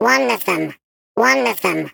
Sfx_tool_spypenguin_vo_love_05.ogg